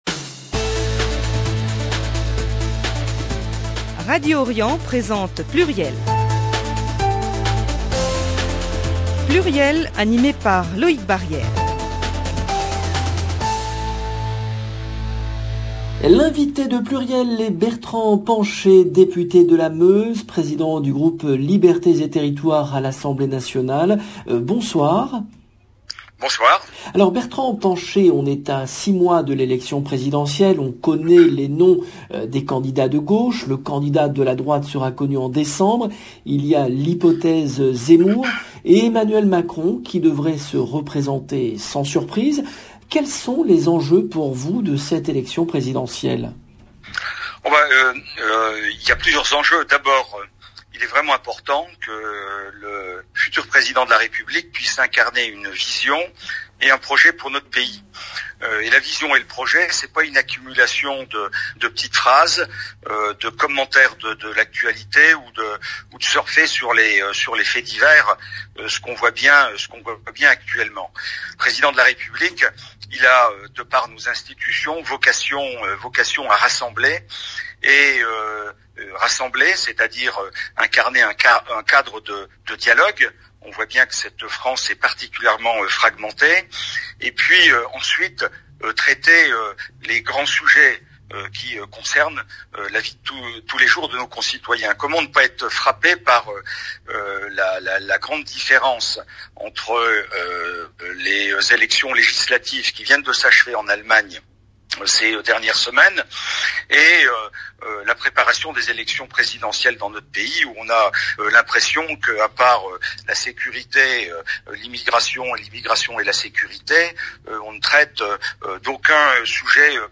PLURIEL, le rendez-vous politique du vendredi 5 novembre 2021
L’invité de PLURIEL est Bertrand Pancher, député de la Meuse, président du groupe Libertés et Territoires à l’Assemblée Nationale, membre du Parti radical.